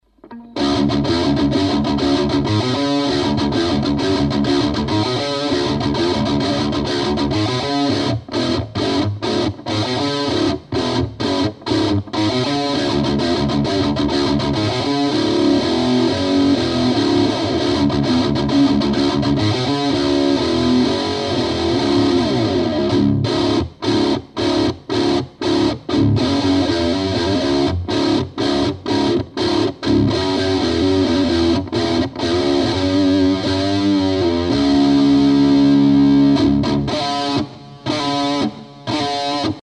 Середина провалена больно.
Семпл ненормальный, жутко гнусавый. Звук пересушен в ноль.